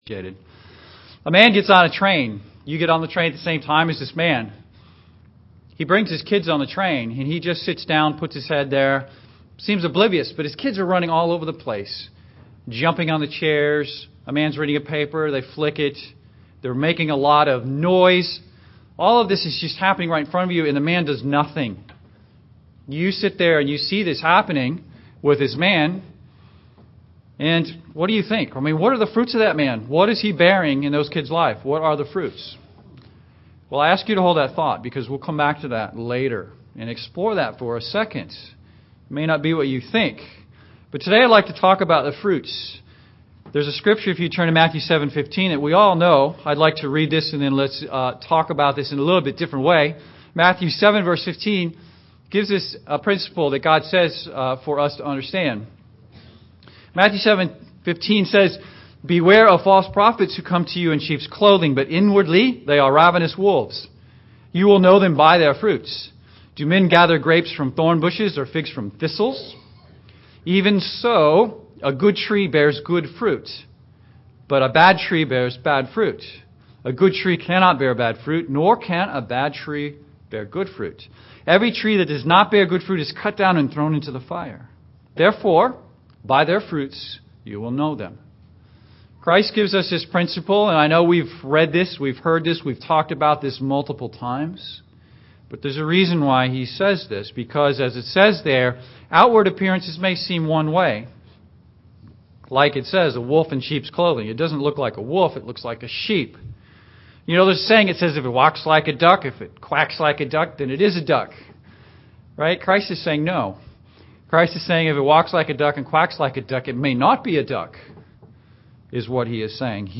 This sermon dives into how to discern spiritual fruits, the importance of the tongue in promoting the right fruit and what you can do to ensure you can see clearly to discern spiritual fruits.